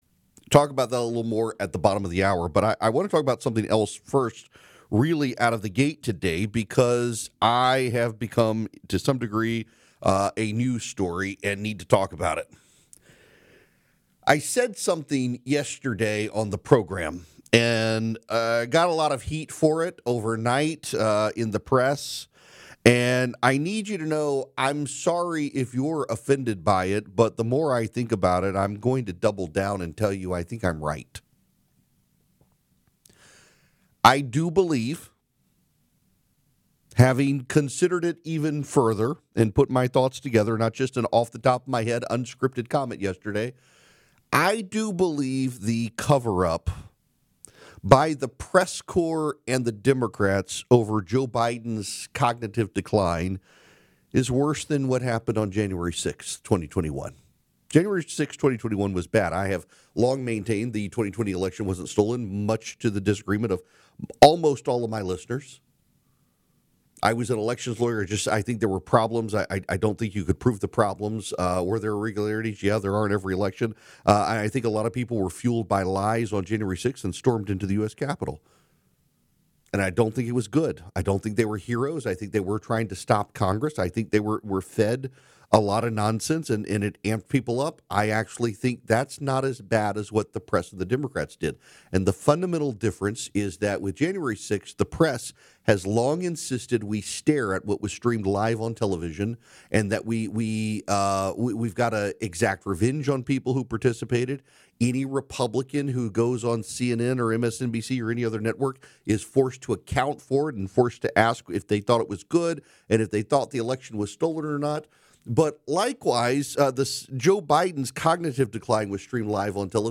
Unfiltered takes on politics, culture, and faith. A lawyer by training, political contributor to leading cable news networks, and now national radio talk show host, Erick Erickson is known for his intelligence, broadcast talents, political insights, and willingness to speak candidly to his growing audience.
Erick_Erickson_Demo_Mono_05-25.mp3